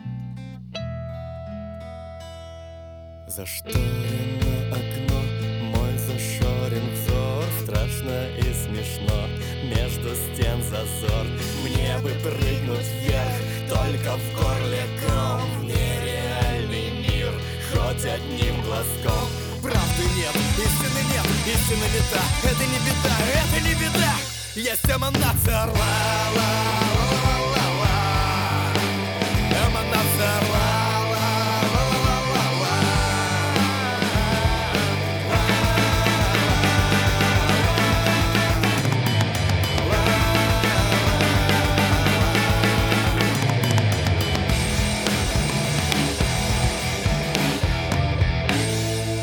И вот куплетная часть звучит вроде как не очень.